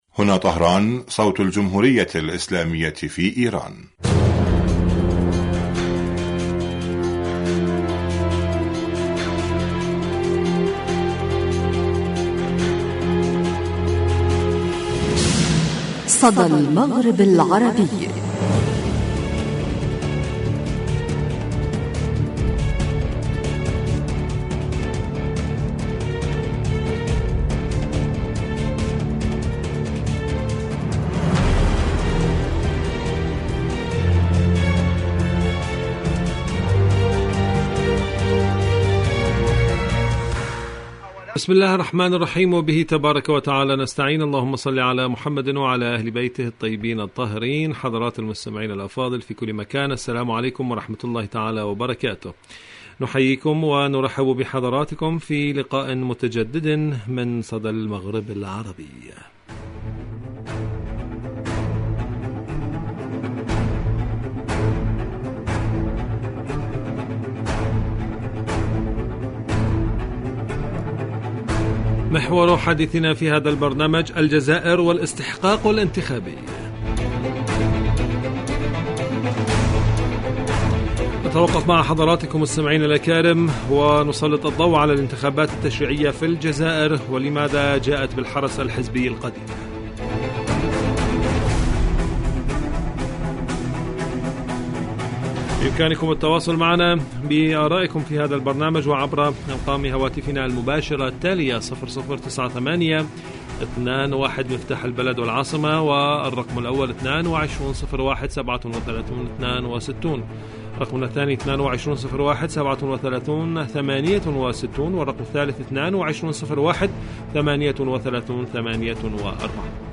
إذاعة طهران-صدى المغرب العربي: ضيفة الحلقة